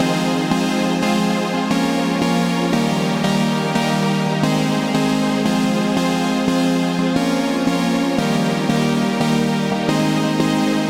灵感合成器BPM88 Ab大调
描述：这个循环适用于POP、EDM音乐等。
Tag: 88 bpm Pop Loops Synth Loops 1.84 MB wav Key : G